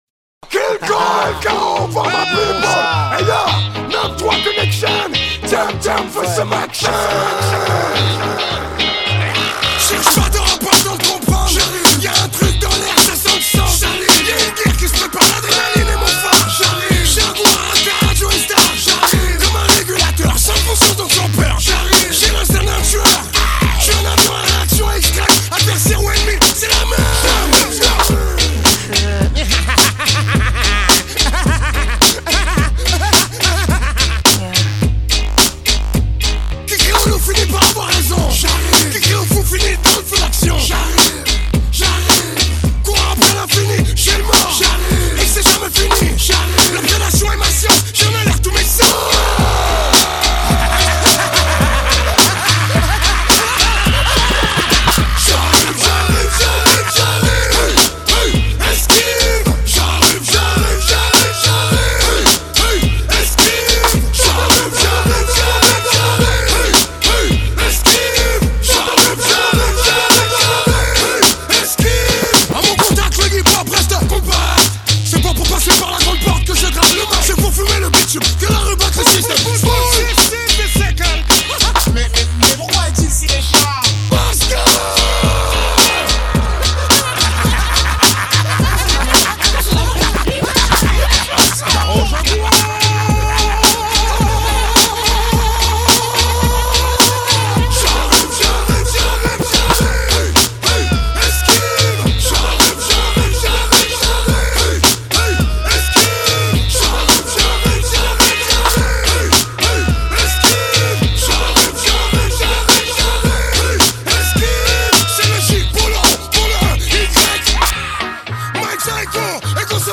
Instrumentale
acapella .